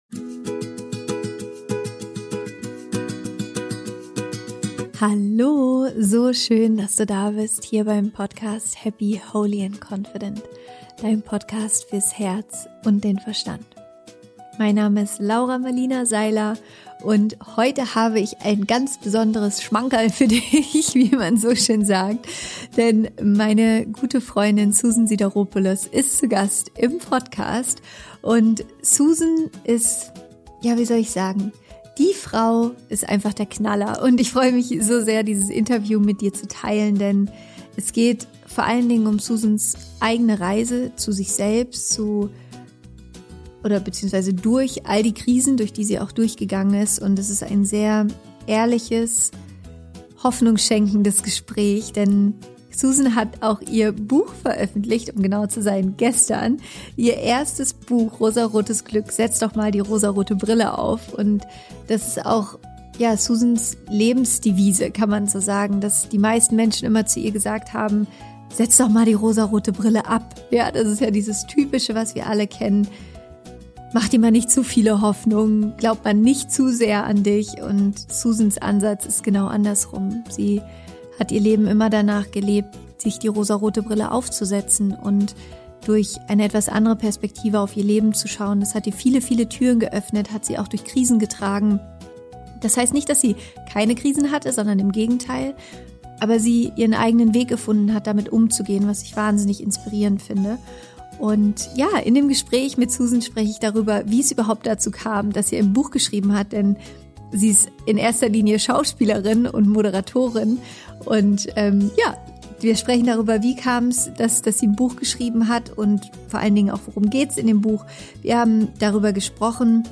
Warum Erfolg im Außen nicht automatisch Glück im Innen bedeutet – Interview Special mit Susan Sideropoulus
Genau darüber spreche ich mit meiner guten Freundin Susan Sideropoulus in meiner neuen Podcastfolge. Susan ist seit 20 Jahren als Schauspielerin und Moderatorin im Fernsehen unterwegs.